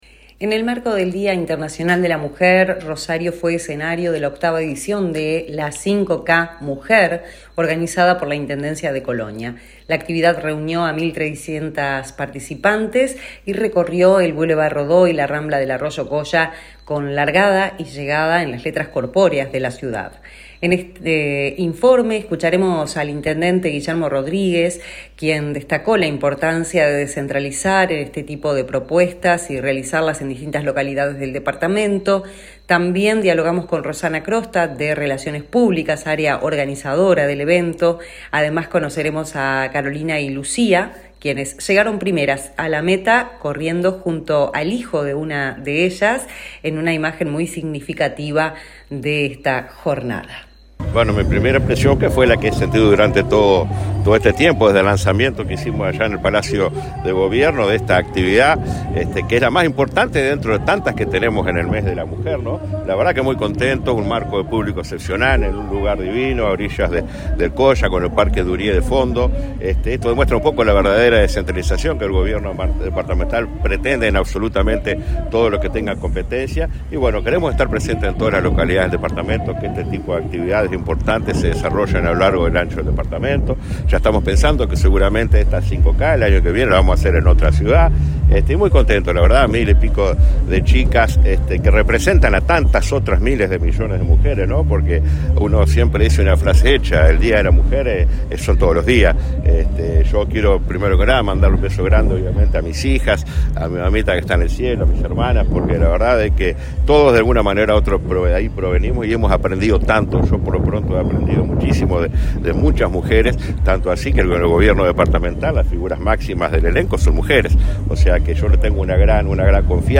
En el marco del Día Internacional de la Mujer, Rosario fue escenario de la octava edición de la 5K Mujer, organizada por la Intendencia de Colonia. La actividad reunió a 1300 participantes y recorrió el bulevar Rodó y la rambla del arroyo Colla, con largada y llegada en las letras corpóreas de la ciudad. En el siguiente informe escucharemos al intendente Guillermo Rodríguez, quien destacó la importancia de descentralizar este tipo de propuestas y realizarlas en distintas localidades del departamento.